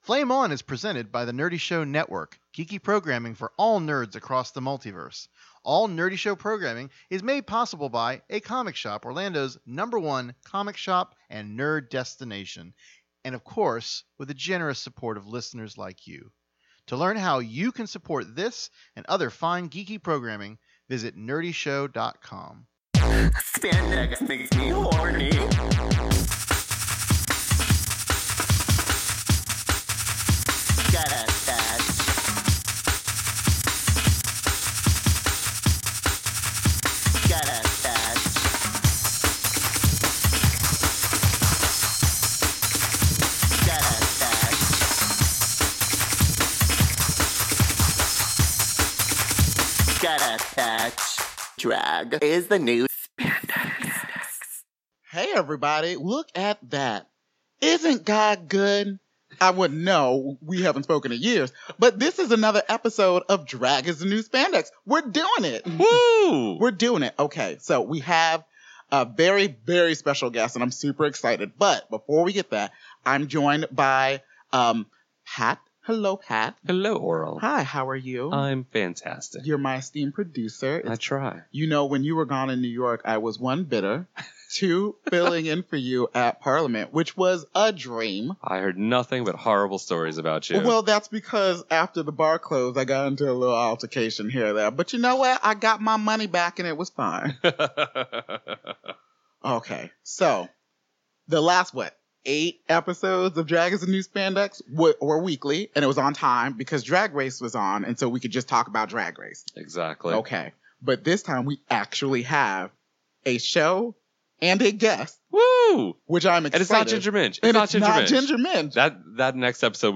Drag Is The New Spandex returns after our All Stars Ru-Caps with a fresh new interview!